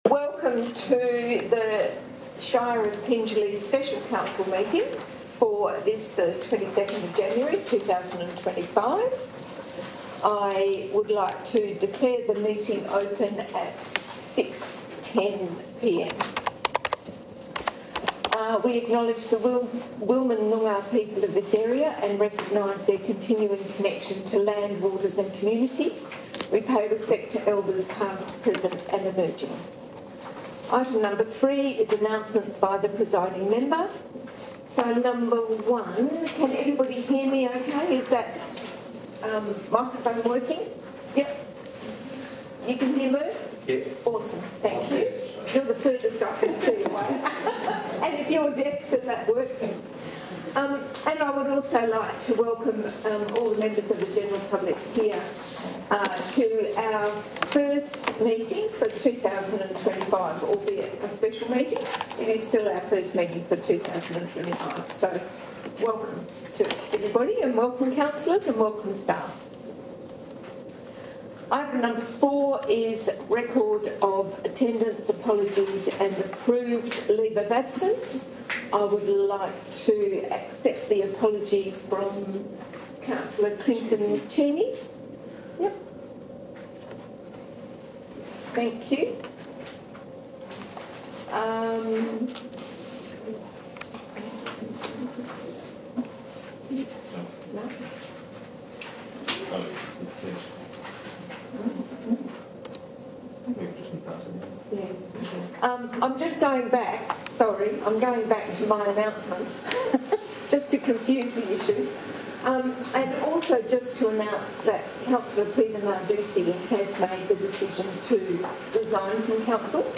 Special Council Meeting Wednesday 22 January, 2025 6:00 PM 22-January-2025-Special-Council-Meeting-Agenda 22 January 2025 Special Council Meeting Minutes (Confirmed) 22 January 2025 Special Council Meeting Website Recording (28.00 MB)